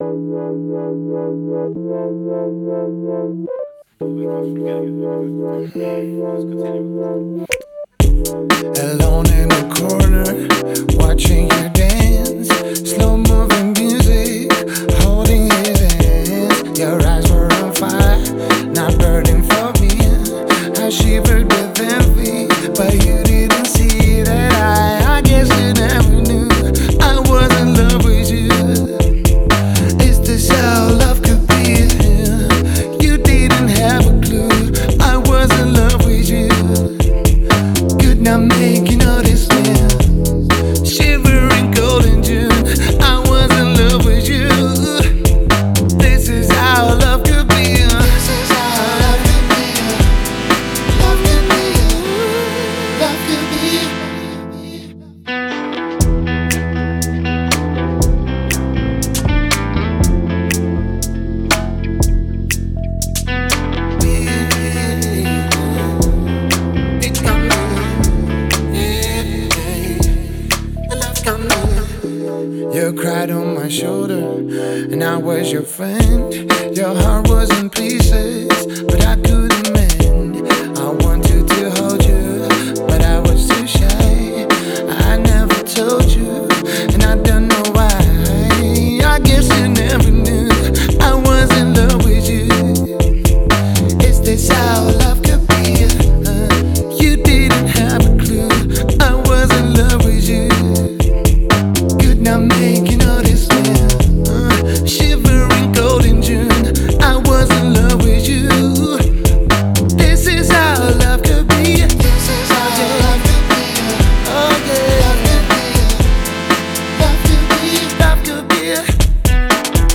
отлично передает атмосферу легкости и свободы